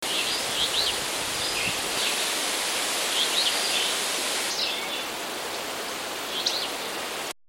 See if you can identify the birds singing, all recorded during the trip: